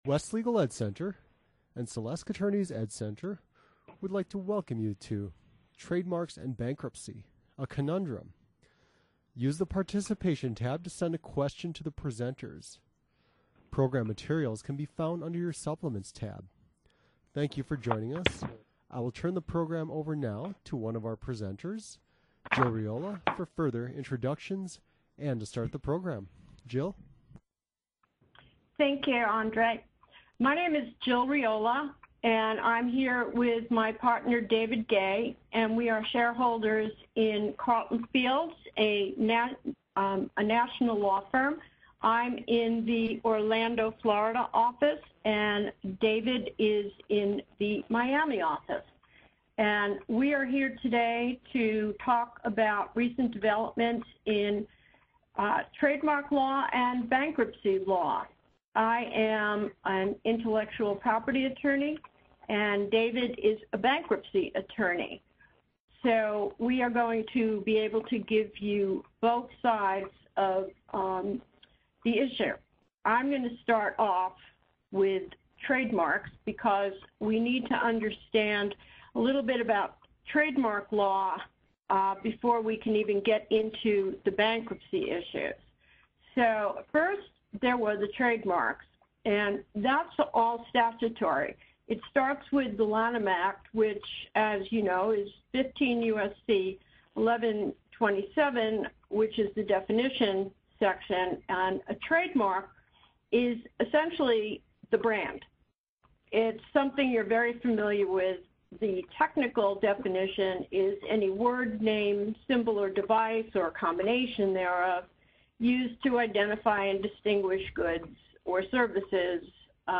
Listen to webinar audio: Trademarks and Bankruptcy: A Conundrum